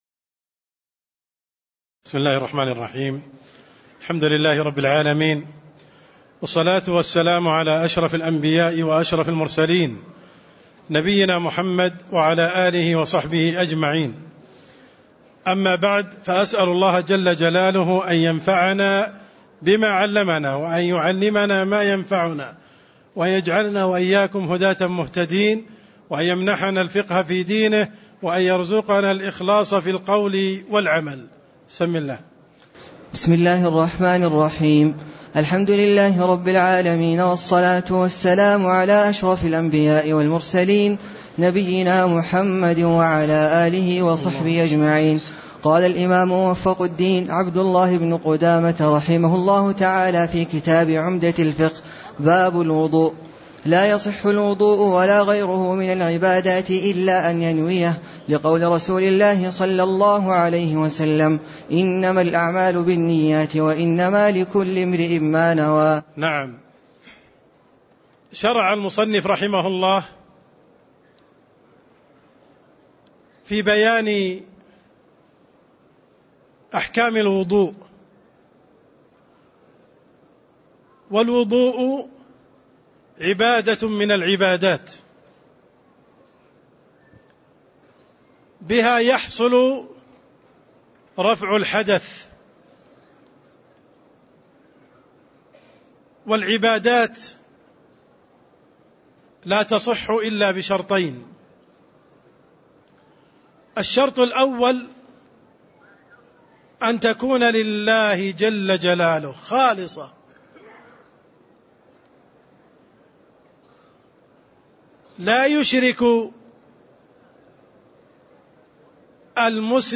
تاريخ النشر ٢٤ جمادى الأولى ١٤٣٥ هـ المكان: المسجد النبوي الشيخ: عبدالرحمن السند عبدالرحمن السند باب الوضؤ (06)